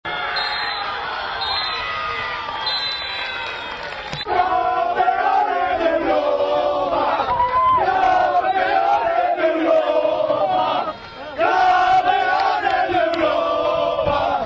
Con el pitido final